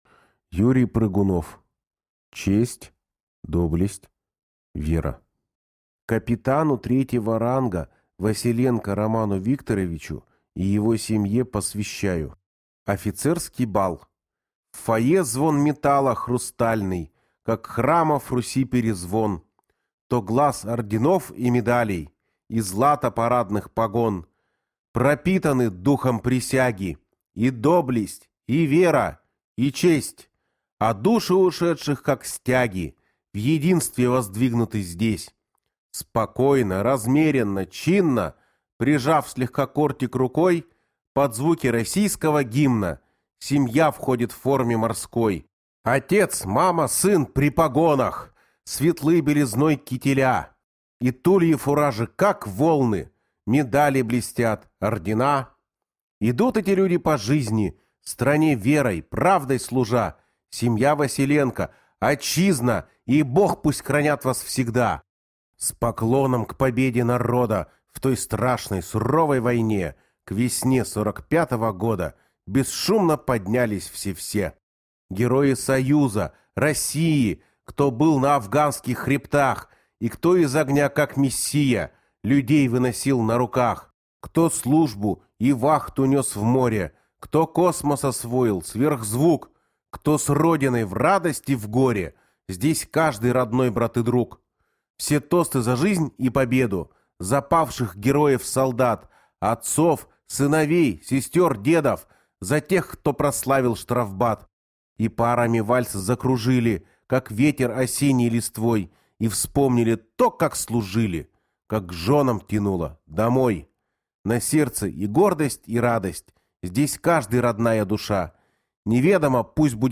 Аудиокнига Честь. Доблесть. Вера | Библиотека аудиокниг